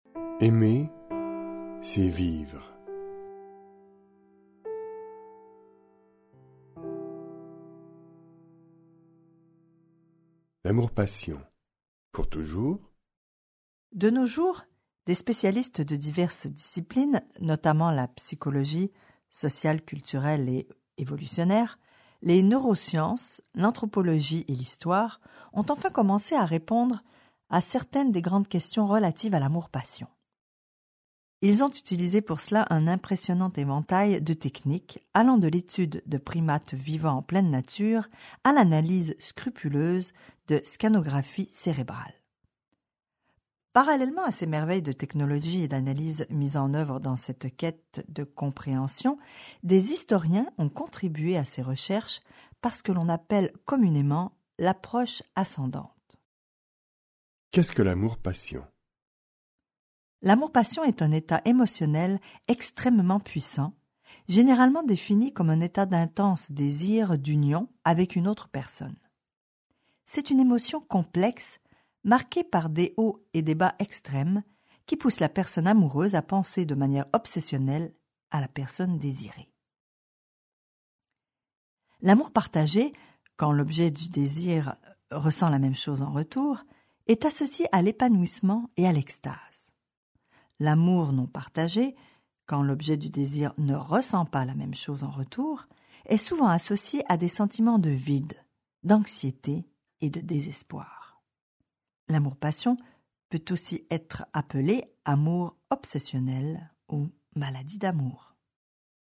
Dans ce livre audio, plus de cent auteurs du monde entier dévoilent les secrets et se penchent sur les mécanismes de l’amour.